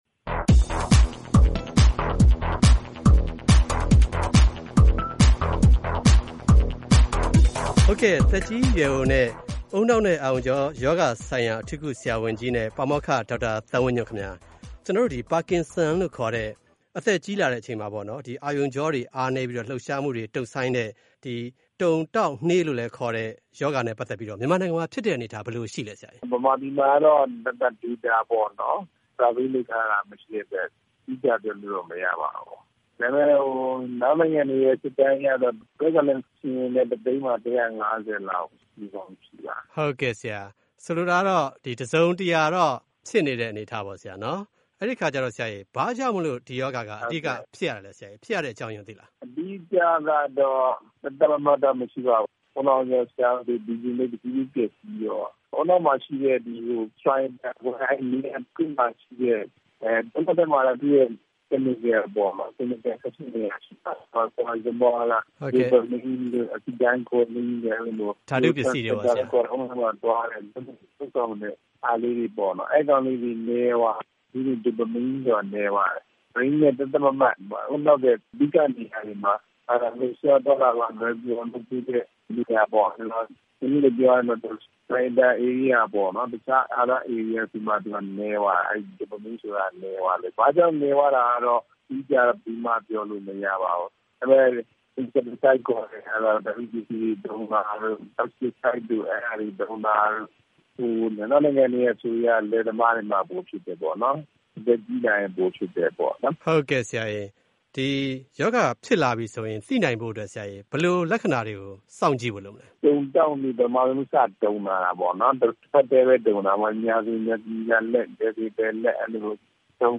သက်ကြီးရွယ်အိုနဲ့ ဦးနှောက်အာရုံကြောရောဂါဆိုင်ရာ အထူးကု၊ ပါမောက္ခ
ဆက်သွယ်မေးမြန်း တင်ပြထားပါတယ်။